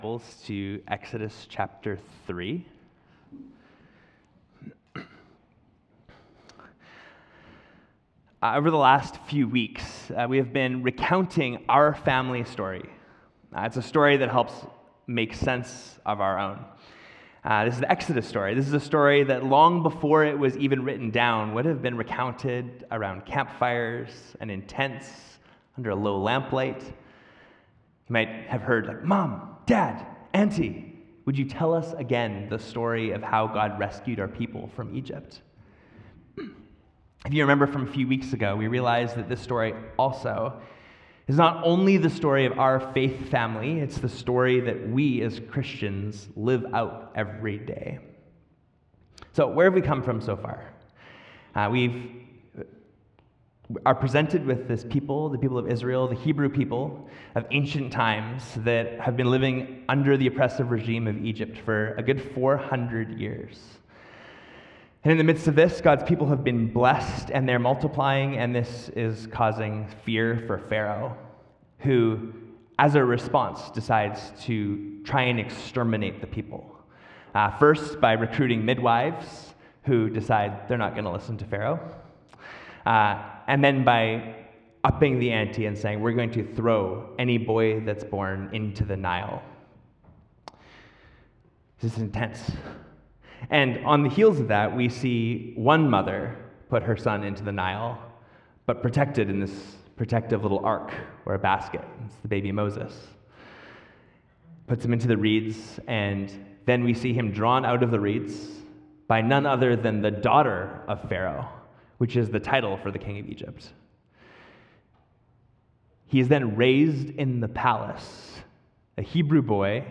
Sermon Series – Hillside Church